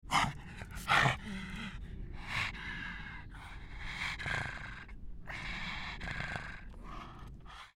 Звуки мопса
звук злящегося мопса